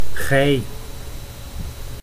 Ääntäminen
Synonyymit gijlieden gelui gijlui jelui Ääntäminen Tuntematon aksentti: IPA: /ɣɛi̯/ Haettu sana löytyi näillä lähdekielillä: hollanti Käännöksiä ei löytynyt valitulle kohdekielelle.